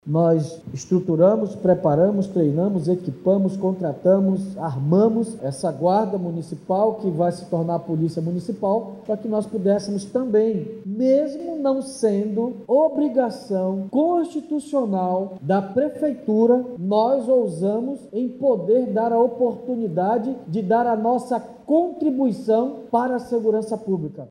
Durante o início da capacitação, na sede da Prefeitura de Manaus, o prefeito Davi Almeida disse que essa é mais uma etapa do processo de transformação da Guarda Municipal em Polícia do Município.